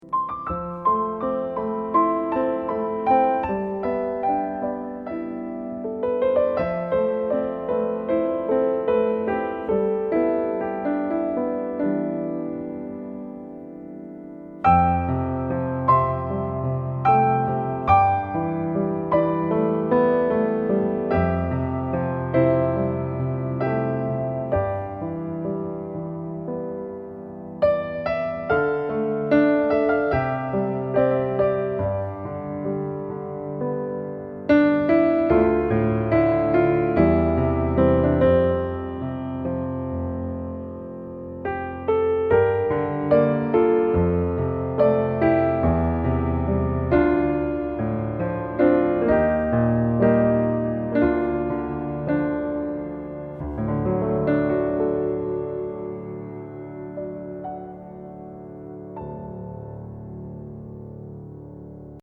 Level : Easy | Key : F | Individual PDF : $3.99